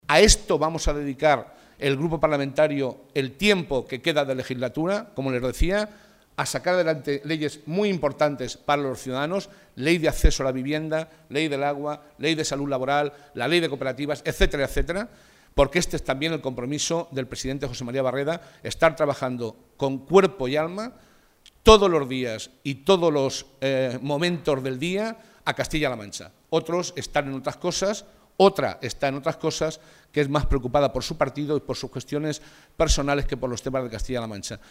Momento de la rueda de prensa celebrada en la sede del PSOE de Albacete